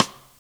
RIM_loud_01.WAV